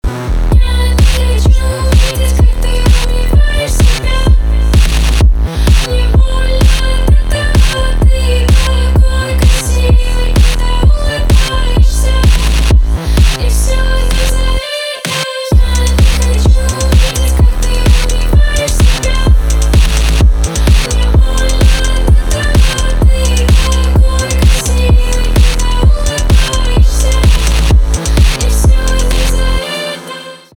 инди
грустные , печальные